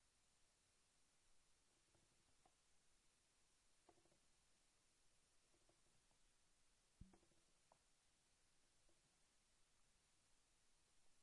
标签： 约德尔 尖叫 陈词滥调 刻板 威廉 尖叫 男人 电影 卡通
声道立体声